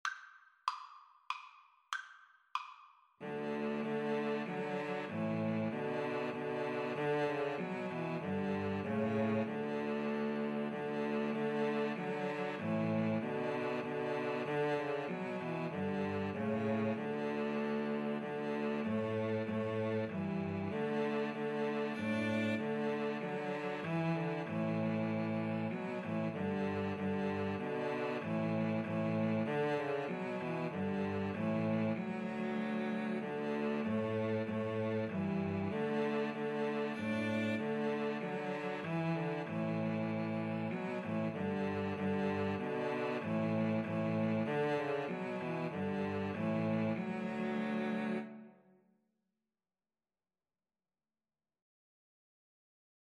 Free Sheet music for Cello Trio
Cello 1Cello 2Cello 3
D major (Sounding Pitch) (View more D major Music for Cello Trio )
Andante expressivo = c. 96
3/4 (View more 3/4 Music)
Classical (View more Classical Cello Trio Music)